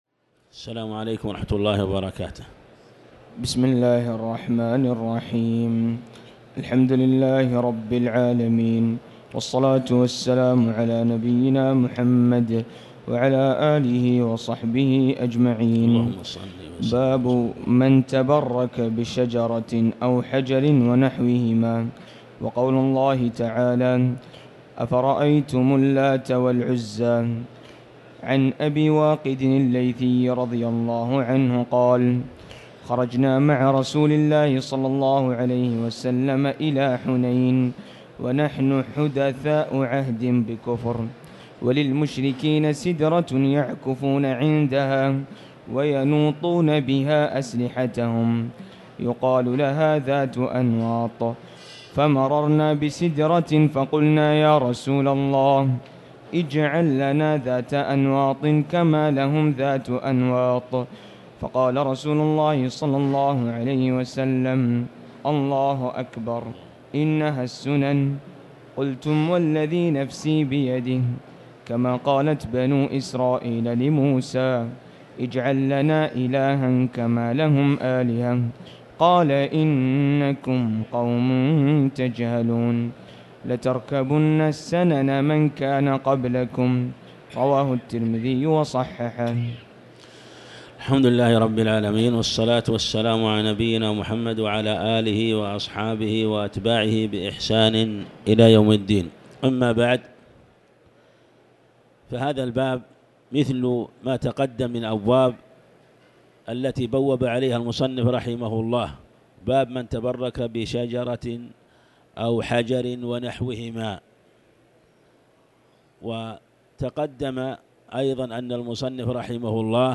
تاريخ النشر ١٠ رمضان ١٤٤٠ هـ المكان: المسجد الحرام الشيخ